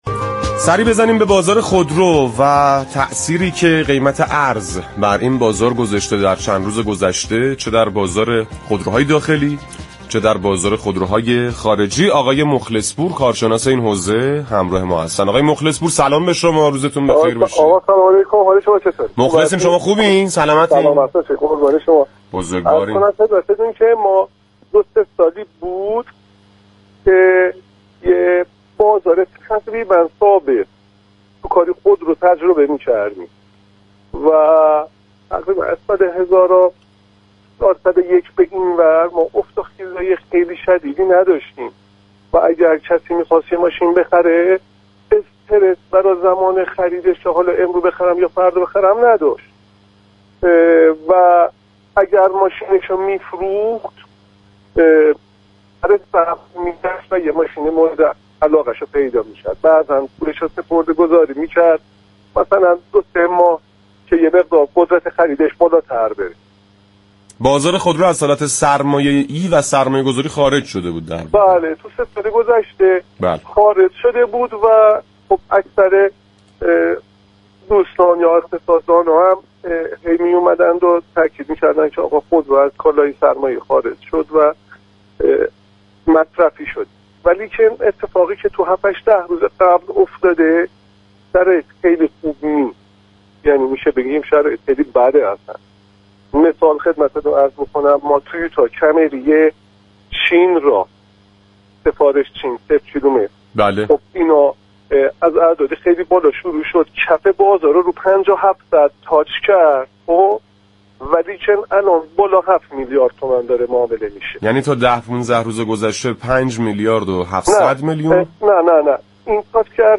كارشناس بازار خودرو در گفت‌وگو با خبرنگار ما